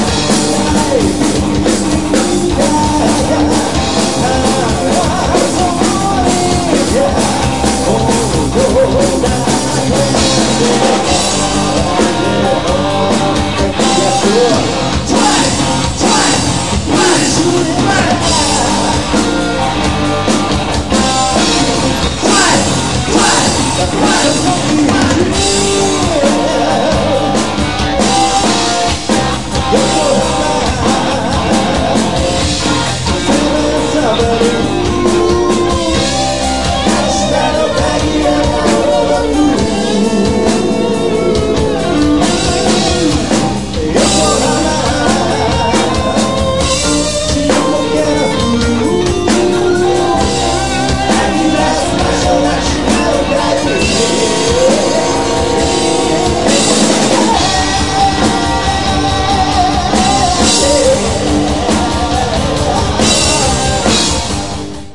例によってトラブルがあったり気合いが入りすぎて走り気味だったりしたものの、久しぶりにしては決めも比較的カチッと決まり、持ち前の歌謡ロックが良かったのか、観客のオバサンから「すごく良かった」と握手される一幕もありました。
vocal
guitar
keybords,chorus
bass,chorus
drums